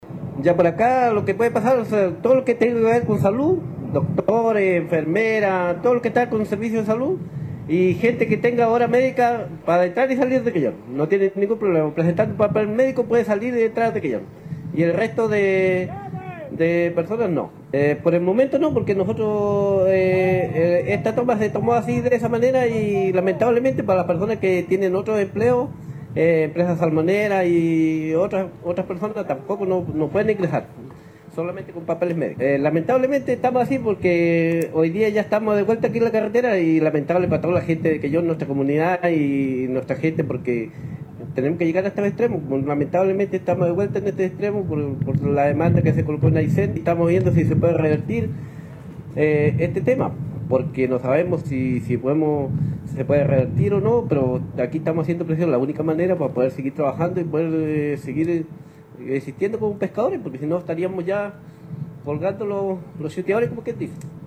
De esta forma se manifiestan los pescadores en el lugar de la toma de carretera, donde mantienen cortado el tránsito desde anoche, reclamando por la posición adoptada por la Corte de Apelaciones de Coyhaique.